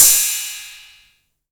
808CY_6_TapeSat.wav